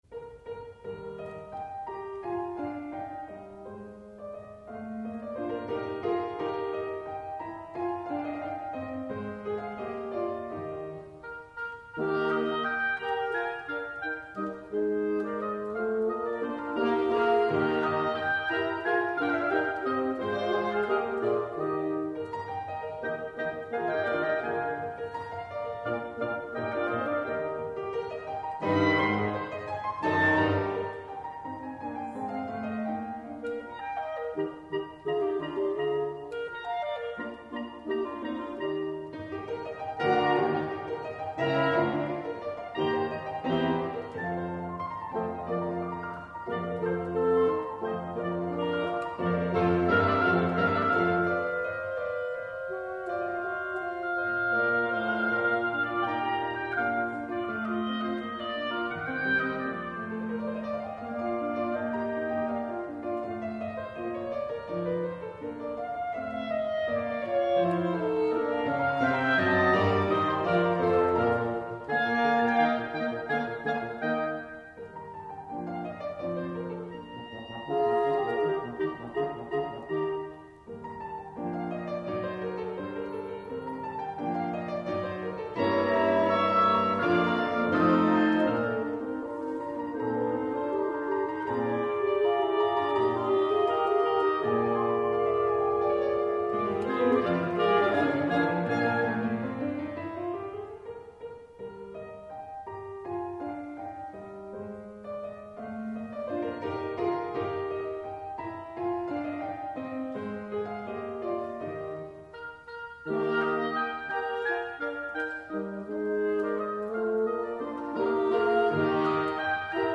bright and cheerful third movement